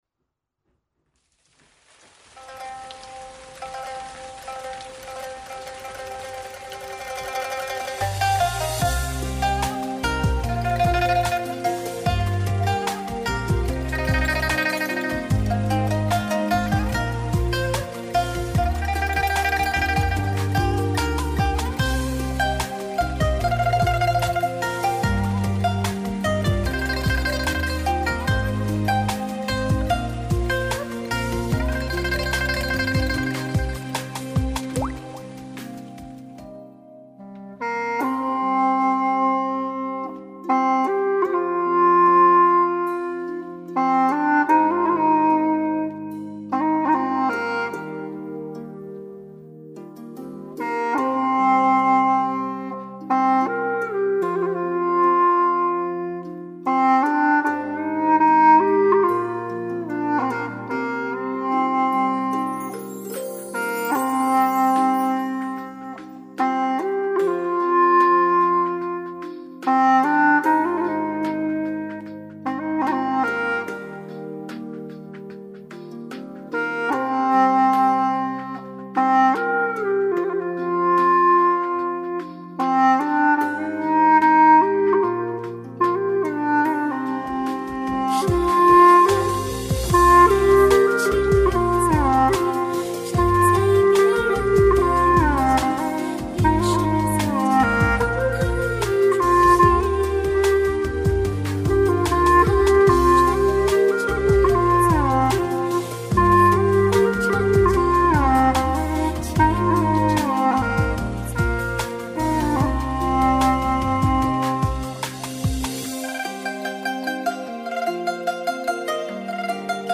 调式 : 降E 曲类 : 古风
【原调降E】